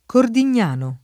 [ kordin’n’ # no ]